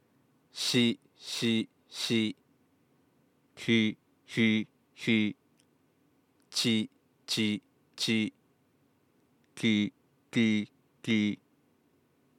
少し大げさですが普通の発音の後に側音化構音っぽく発音した音源を用意してみましたので、一度聞いてみてください。
側音化構音の発音例
音量注意！
この参考音源くらい明確に発音に異常がある人はそんなに多くないのかもしれませんが、これに準ずる状態になっている人はかなり多い印象です。
lateral-lisp-01.mp3